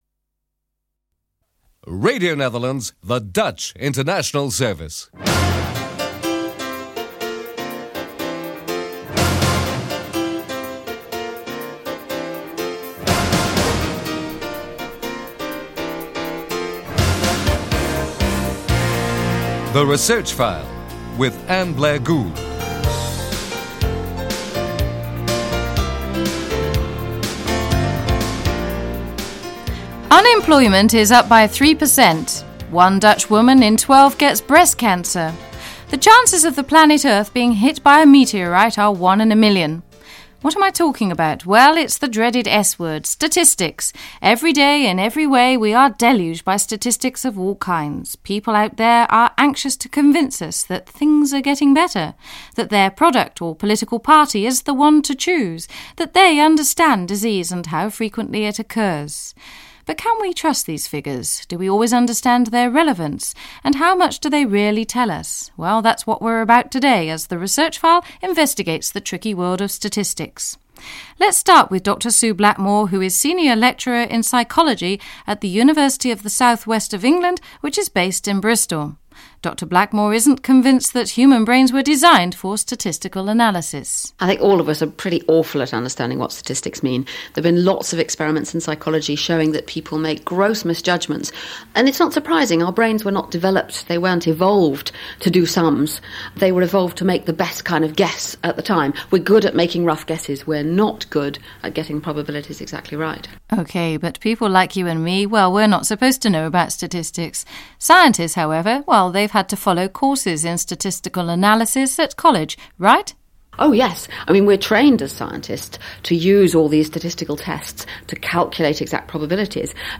This edition of our weekly science magazine “Research File” focuses on statistics.
This is not just a witty quote but contains some truth for, as we hear from our guests, there are good reasons to practice caution when interpreting statistics and when guessing the probability of something or drawing conclusions.